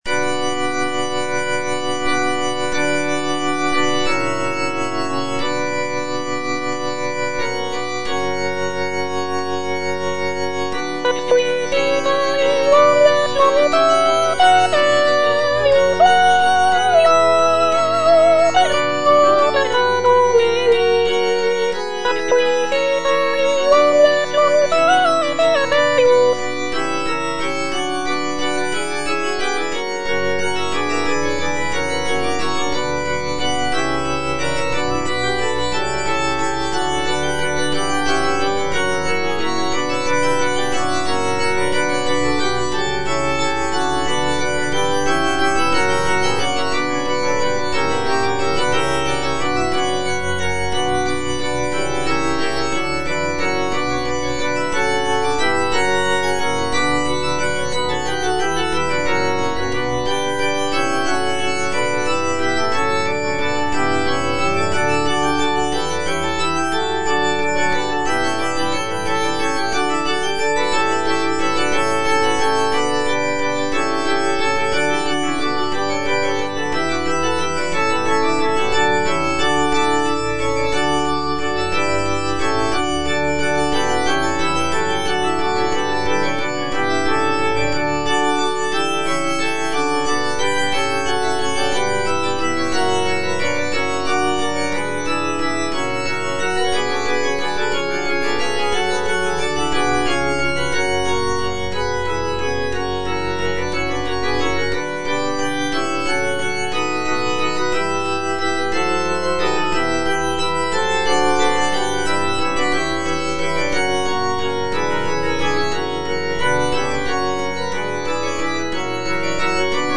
M.R. DE LALANDE - CONFITEBOR TIBI DOMINE Magna opera Domini (petit choeur - soprano I) (Voice with metronome) Ads stop: auto-stop
"Confitebor tibi Domine" is a sacred choral work composed by Michel-Richard de Lalande in the late 17th century.
Lalande's composition features intricate polyphony, lush harmonies, and expressive melodies, reflecting the Baroque style of the period.